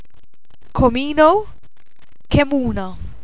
Aussprache )